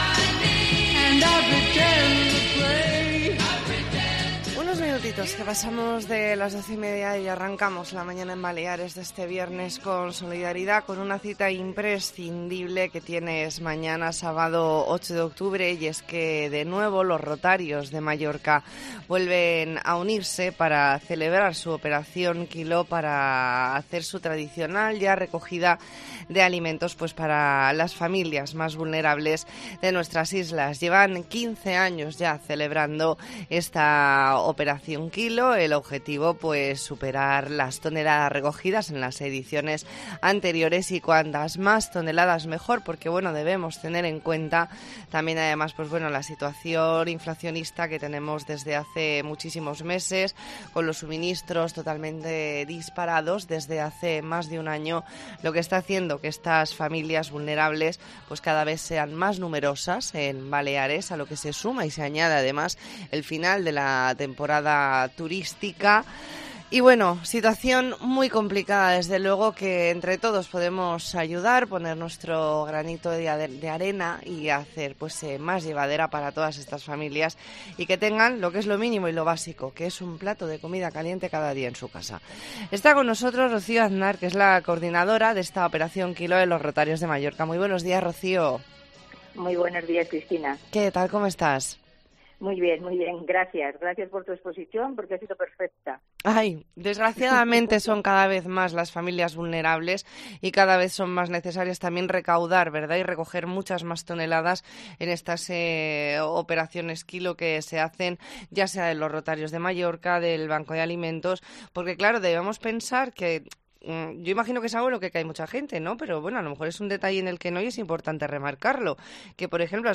E ntrevista en La Mañana en COPE Más Mallorca, viernes 7 de octubre de 2022.